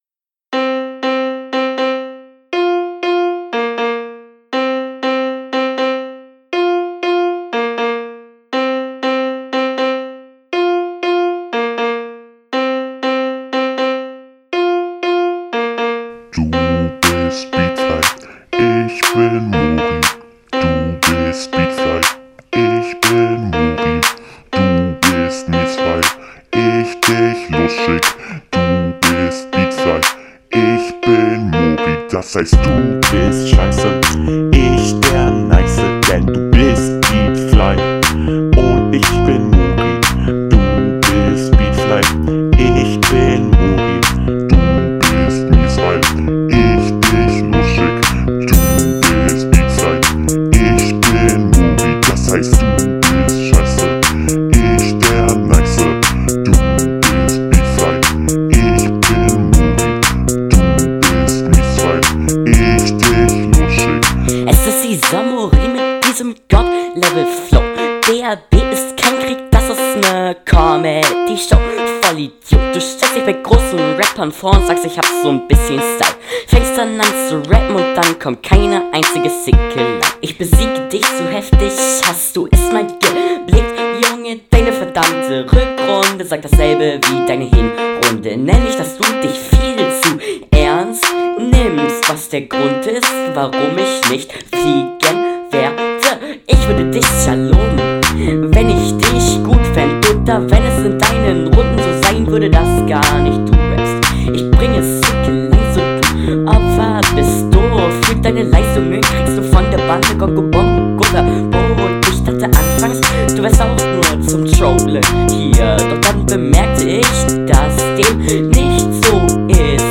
Beat wieder total daneben. 3 Jähriger auf einem Keyboard.